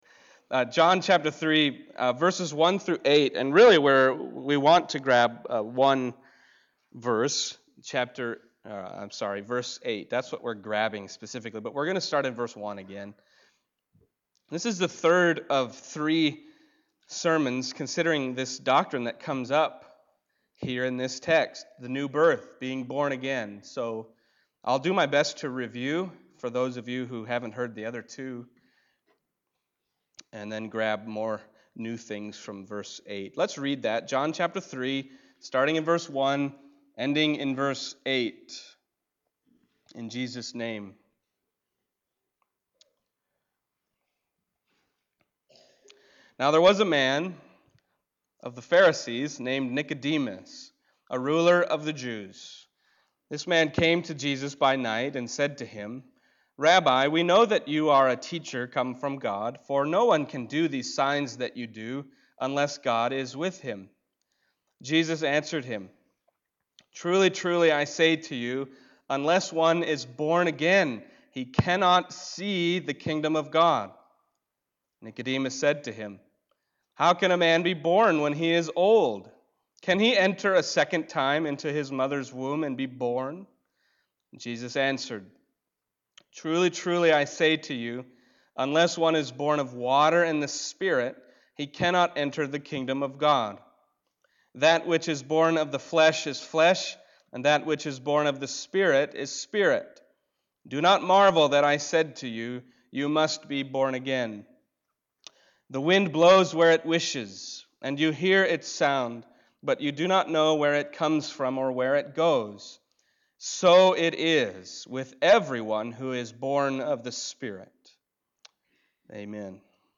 John Passage: John 3:1-8 Service Type: Sunday Morning John 3:1-8 « You Must Be Born Again